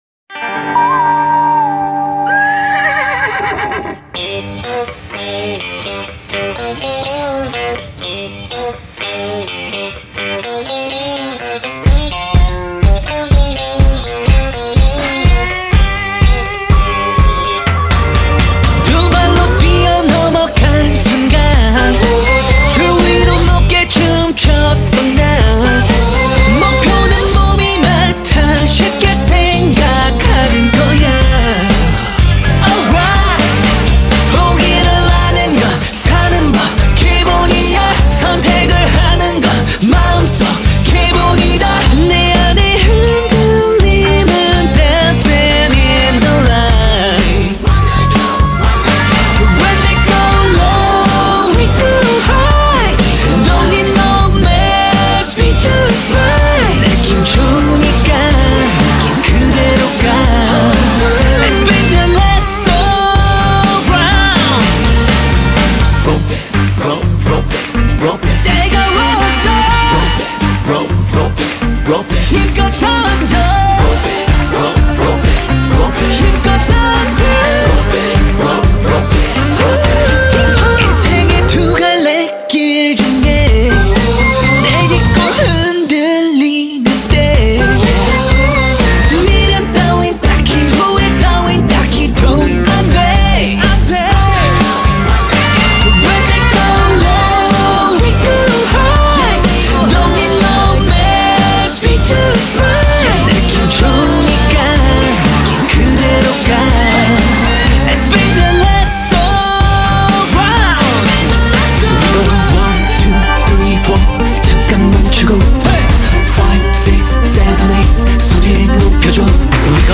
کی پاپ